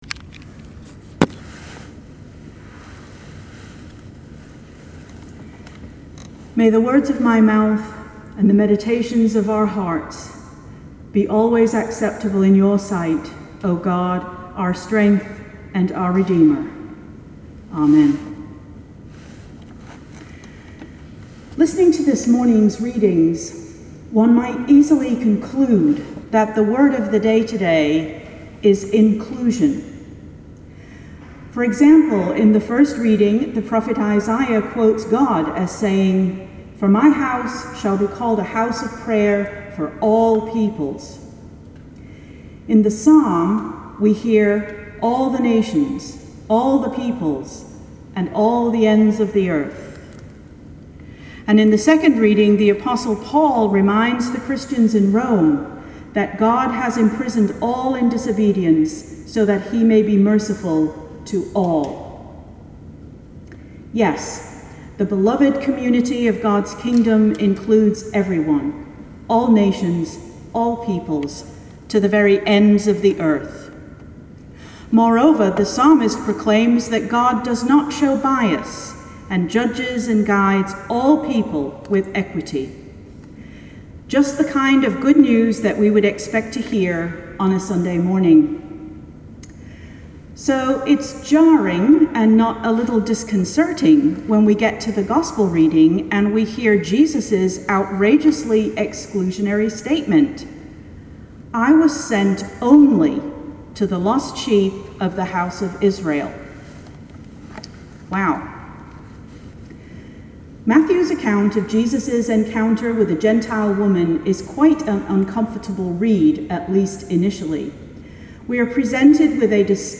In today’s sermon